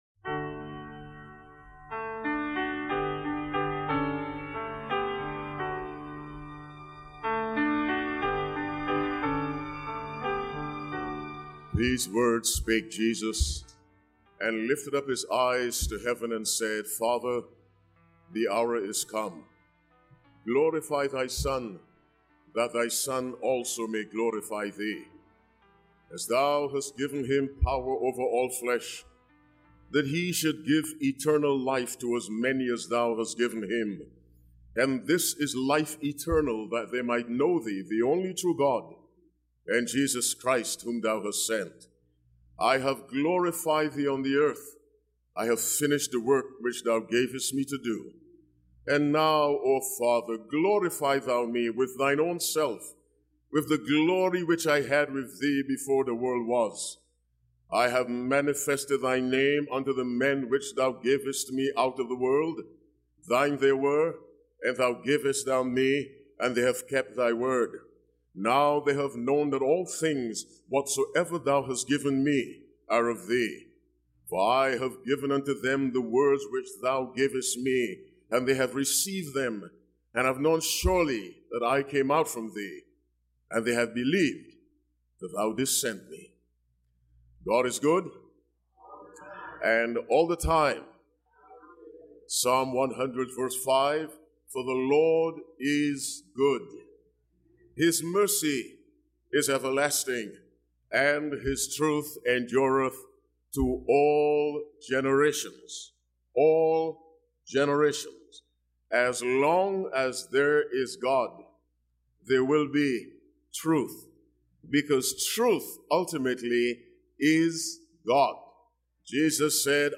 This sermon invites you into a deeper relationship with God, showing that eternal life begins with truly knowing Him—not just believing in Him. Through powerful biblical insights, it explores God's truth, love, provision, and sovereignty, encouraging bold, honest prayer and unwavering trust in His care.